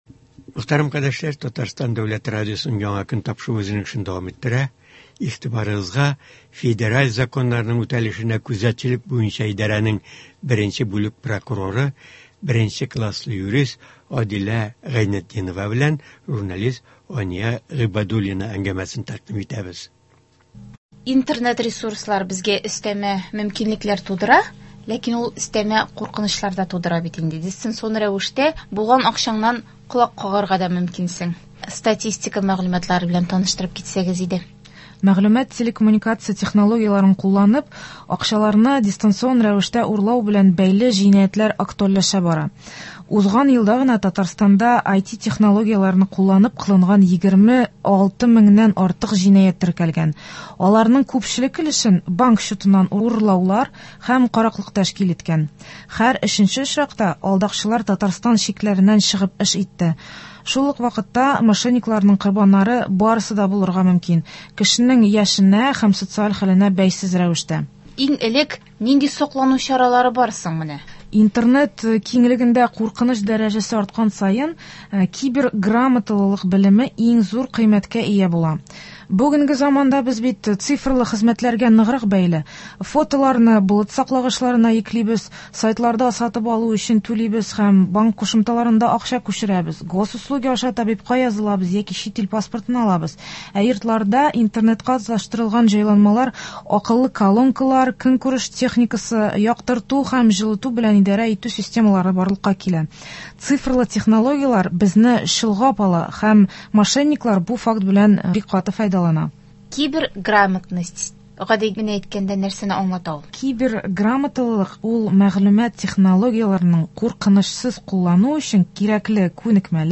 Республикабызда язгы кыр эшләренә әзерлек алып барыла, орлыклар хәзерләнә, техника ремонтлана. Болар хакында турыдан-туры эфирда Татарстан авыл хуҗалыгы министры урынбасары Рафаэль Фәттахов сөйләячәк, тыңлаучылар сорауларына җавап бирәчәк.